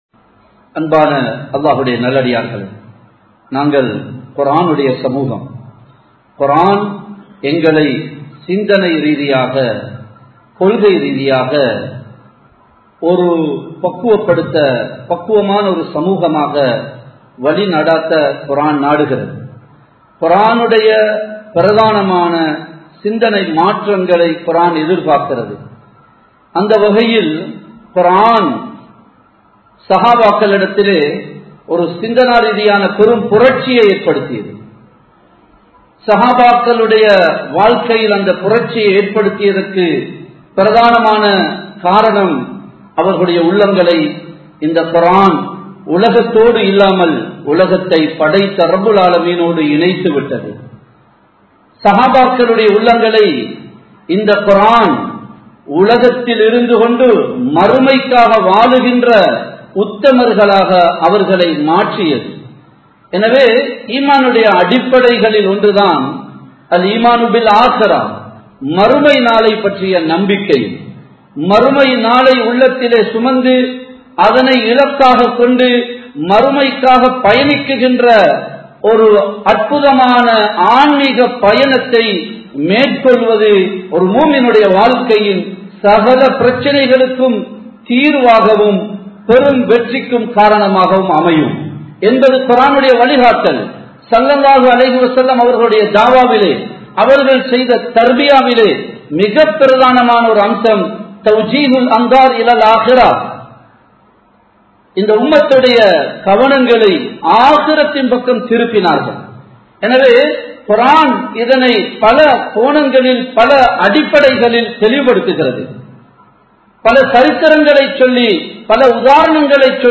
இஸ்லாம் கூறும் வாழ்வியல் | Audio Bayans | All Ceylon Muslim Youth Community | Addalaichenai
Samman Kottu Jumua Masjith (Red Masjith)